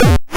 kick_2.mp3